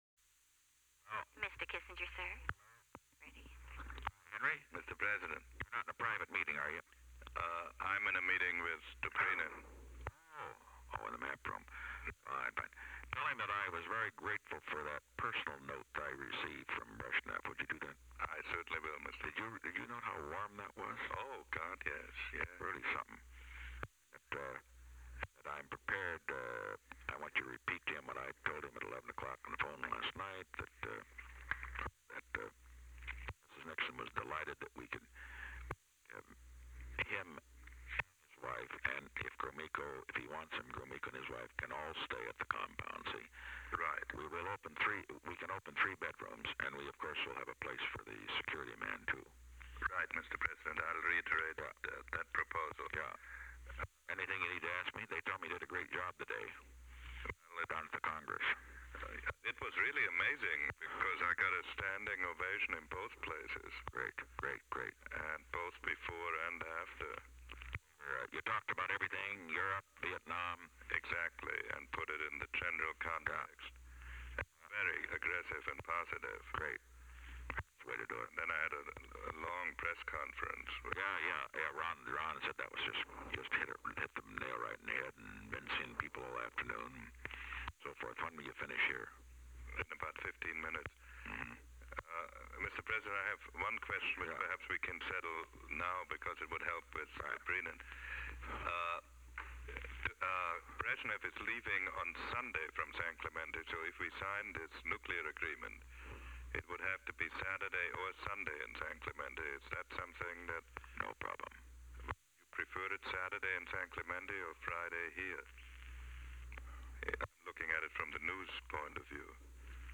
Conversation No. 40-115
Location: White House Telephone
Henry A. Kissinger talked with the President.